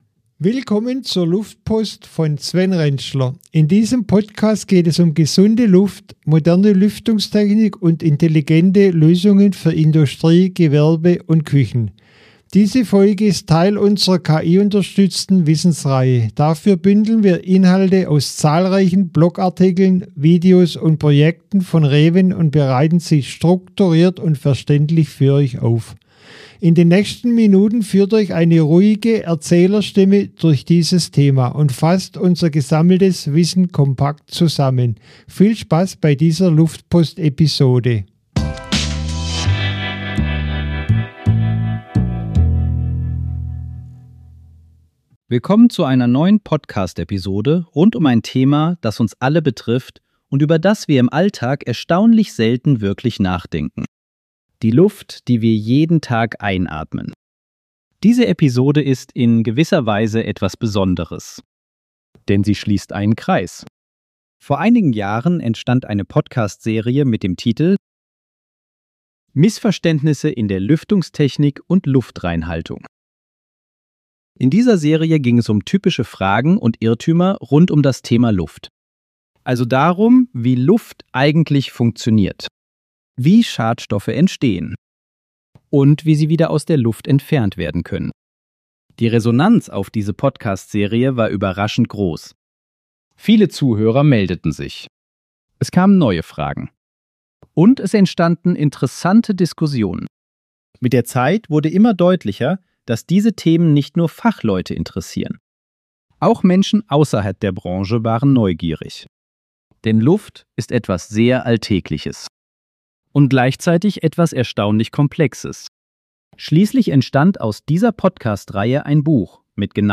In dieser KI-gestützten Podcast-Episode sprechen wir über eines der wichtigsten – und gleichzeitig am häufigsten missverstandenen – Themen der Gebäudetechnik: saubere Luft.